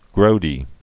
(grōdē)